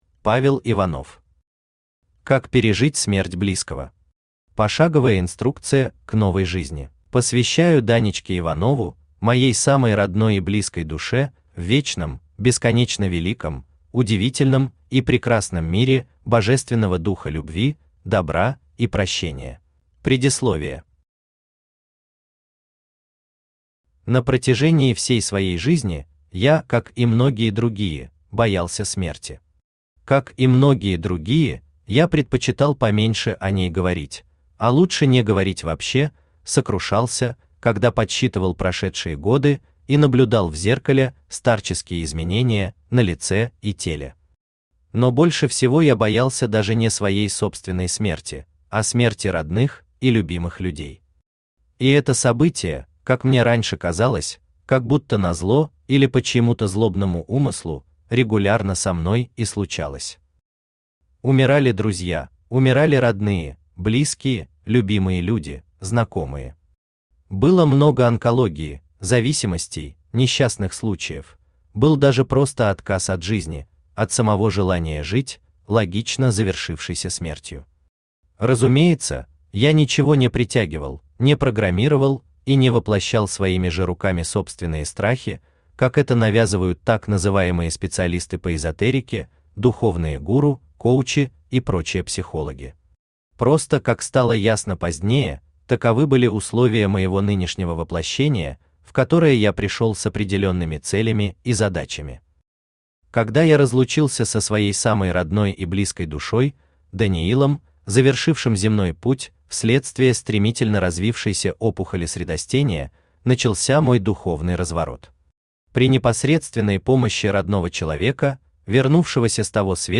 Аудиокнига Как пережить смерть близкого. Пошаговая инструкция к новой жизни | Библиотека аудиокниг
Пошаговая инструкция к новой жизни Автор Павел Иванов Читает аудиокнигу Авточтец ЛитРес.